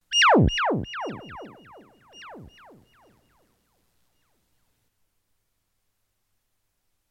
Voz e performance
Instrumentos e eletroacústica
Seu canto é pausado e lírico, mesmo diante do grande perigo.
theremim-4.mp3